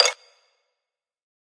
来自我的卧室的声音 "一袋WOD蜡笔（冻结）1
描述：在Ableton中录制并略微修改的声音